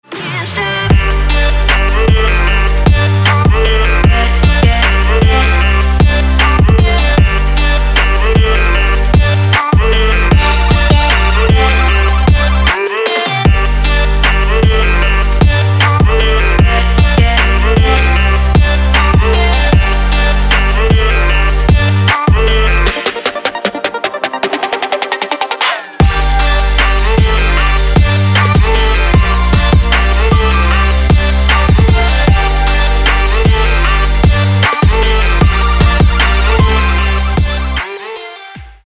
Electronic
Trap
club